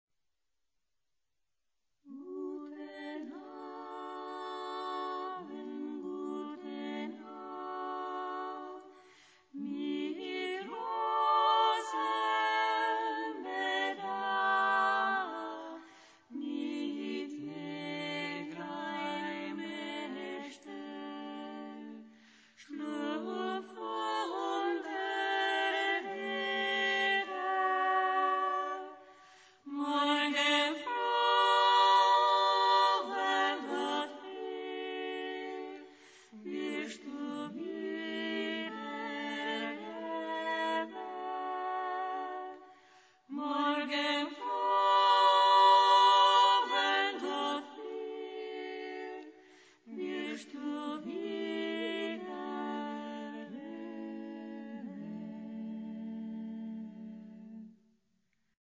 Ricerca, elaborazione, esecuzione di canti popolari emiliani